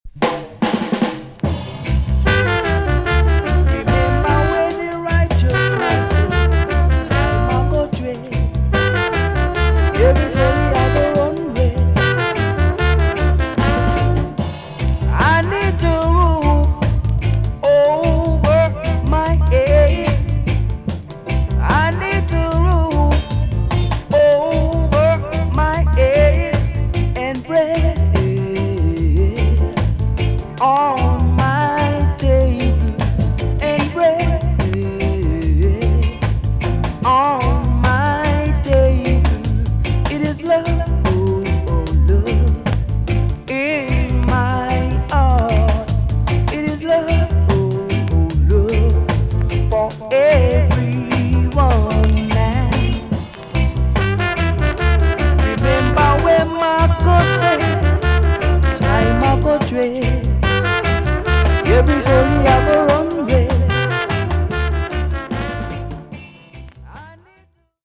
TOP >SKA & ROCKSTEADY
EX- 音はキレイです。